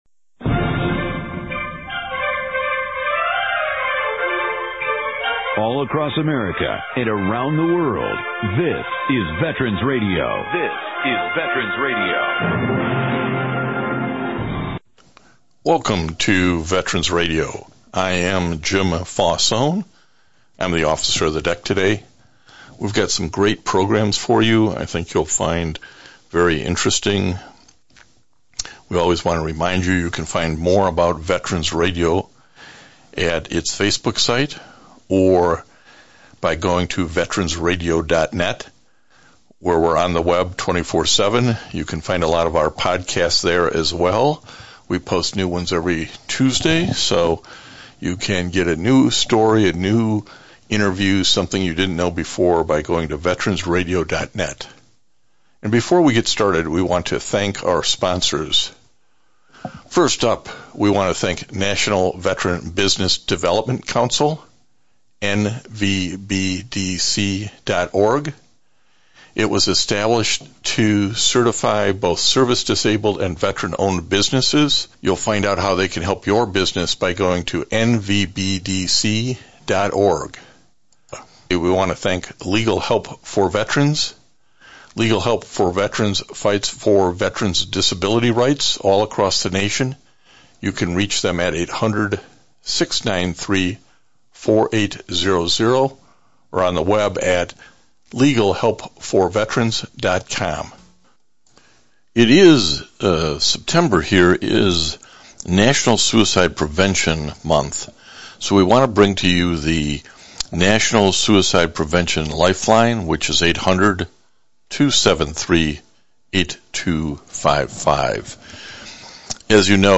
This week’s one hour radio broadcast